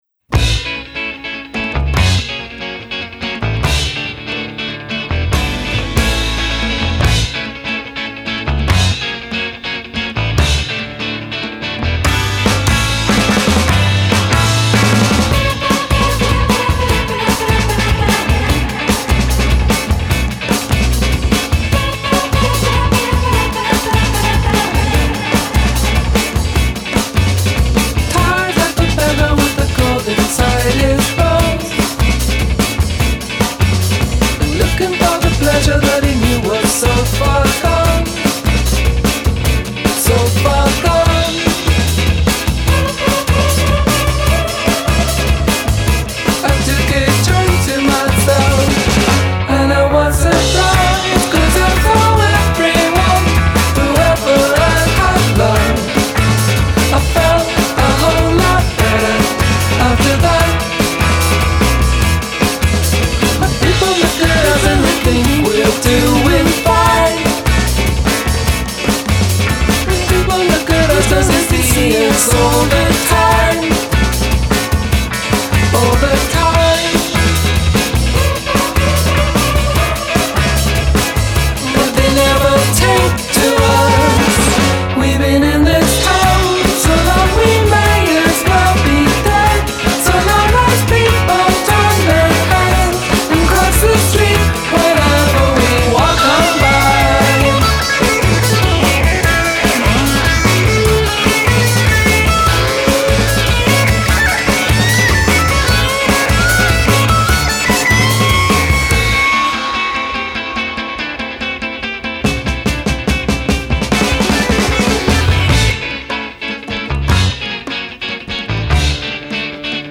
Un attimo fa stavo ballando